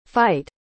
A pronúncia de fight é “fait”: